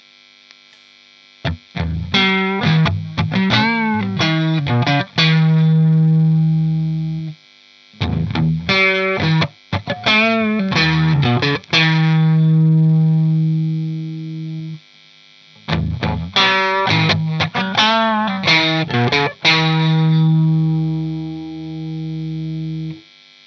guitare (strat tokai ou melody maker ou westone thunder) -> ampli -> cab 2x12 greenback -> micro shure PG57 -> preamp micro -> numérisation (M audio 1010lt)
un petit bémol pour la prise de son sur le coté "pétillant" des samples en satu, en direct c'est pas "pétillant" de l'aigu.
strat crunch les 3 micros (neck, mid, bridge)